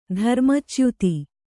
♪ dharma cyuti